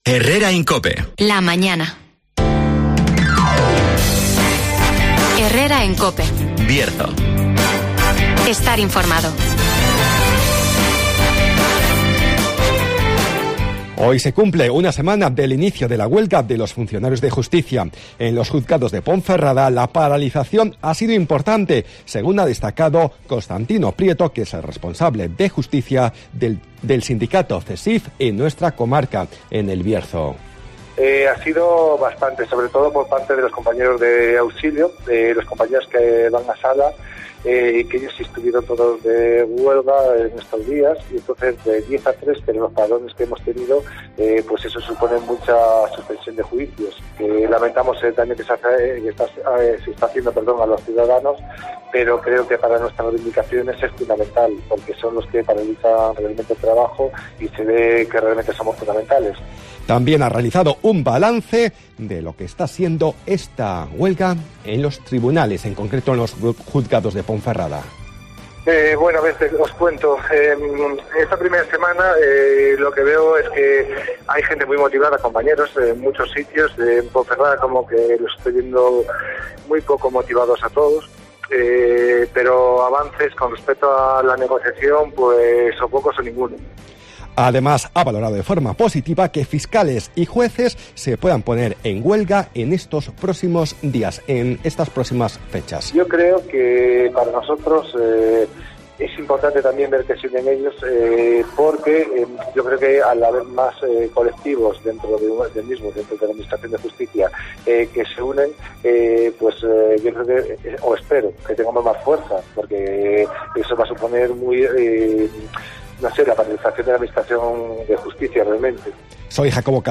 -Resumen de las noticias -El tiempo -Agenda -Entrevista